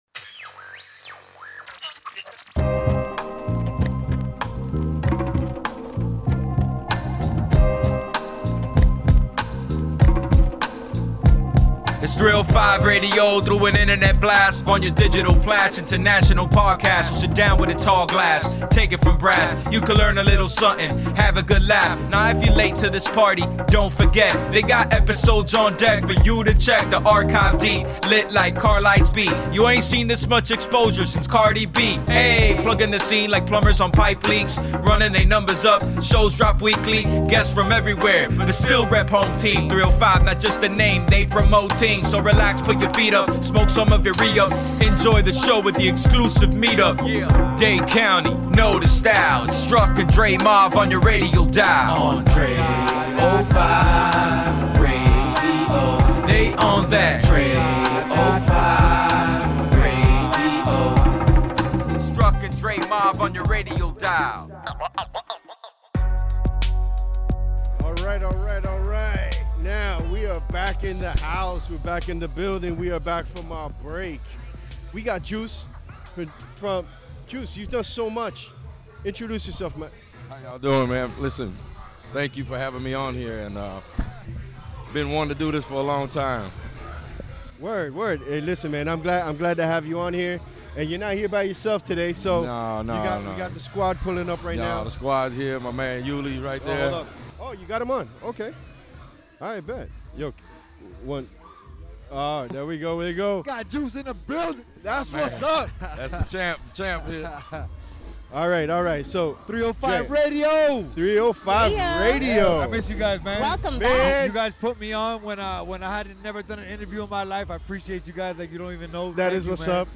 We were recording live at 305 Fest at Oasis in Wynwood.